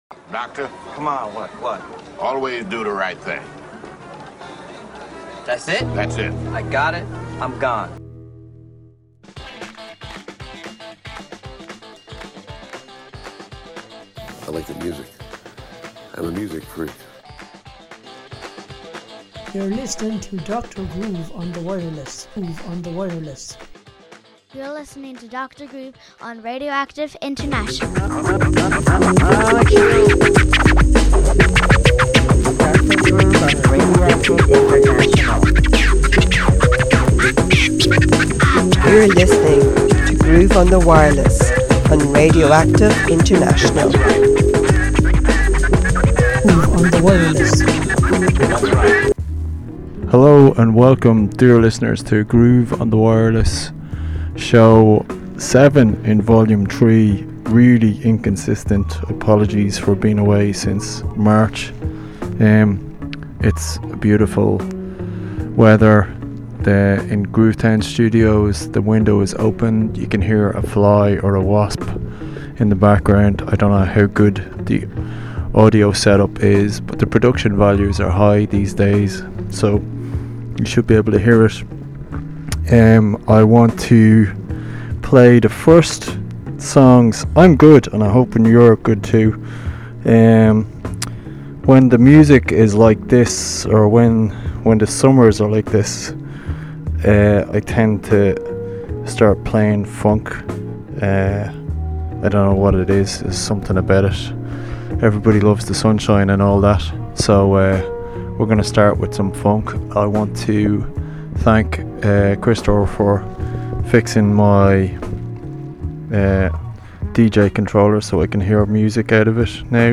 We dive into the FUNK